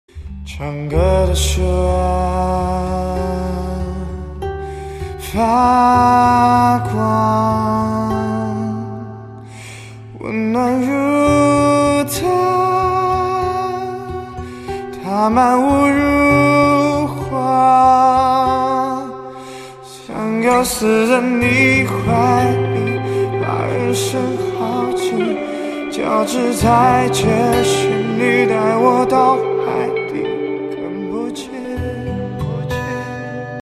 M4R铃声, MP3铃声, 华语歌曲 110 首发日期：2018-05-14 10:20 星期一